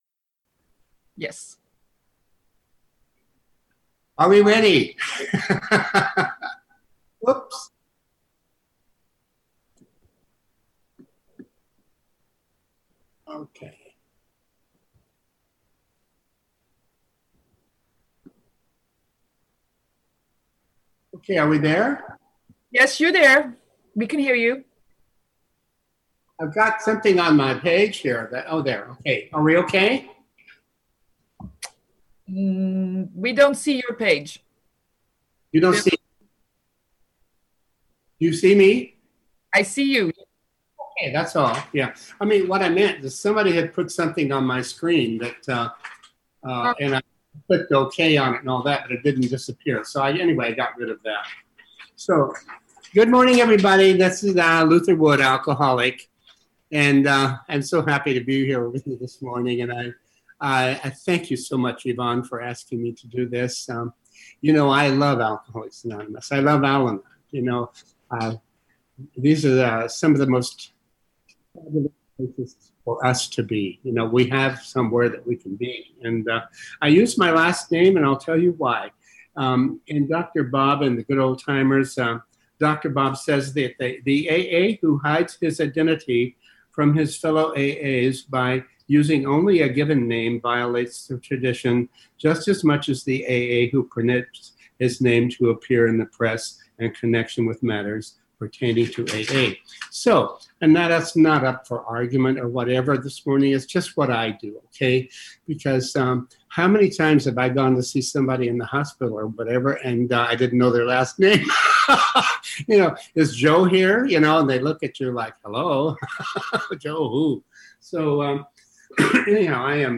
AWB Workshop - Emotional Sobriety